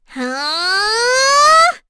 Cecilia-Vox_Casting3.wav